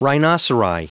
Prononciation du mot rhinoceri en anglais (fichier audio)
Prononciation du mot : rhinoceri